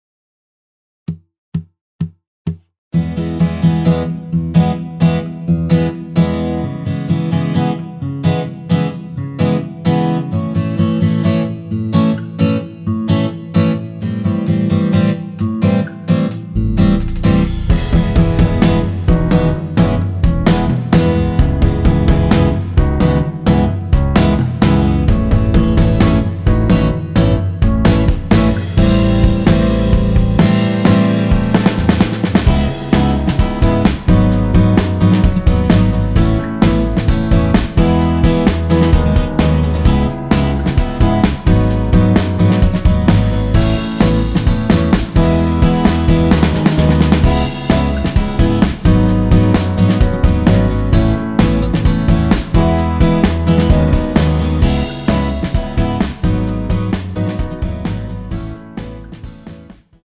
전주 없이 시작하는 곡이라 4박 카운트 넣어 놓았습니다.(미리듣기 확인)
원키에서(-1)내린 MR입니다.
앞부분30초, 뒷부분30초씩 편집해서 올려 드리고 있습니다.